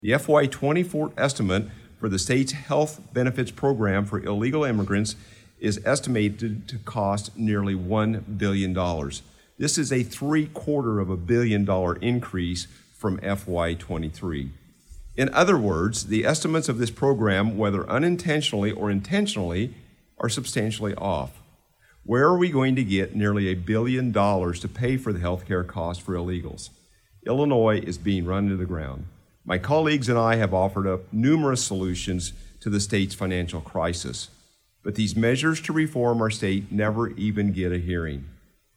Shelbyville, IL-(Effingham Radio)- State Representative Brad Halbrook (R-Shelbyville) said today in a capitol press conference that one way the majority party is making the state of Illinois worse is through excessive spending.